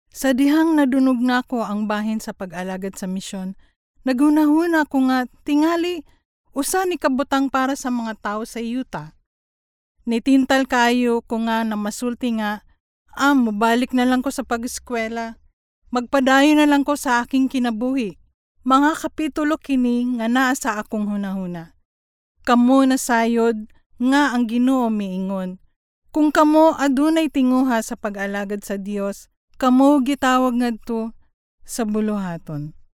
CEBUANO FEMALE VOICES
female